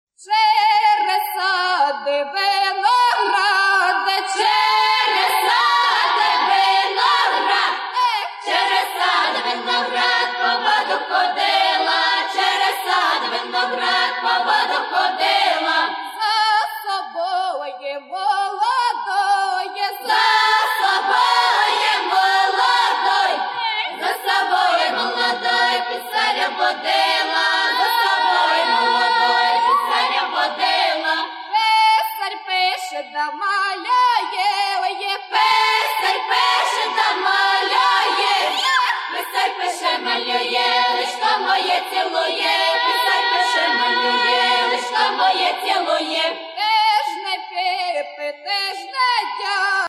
Народна (248)